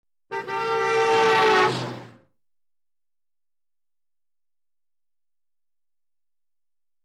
Звуки гудка автомобиля
Звук проезжающей машины с сигналом